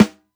SNARE_DANCE_WIT_ME.wav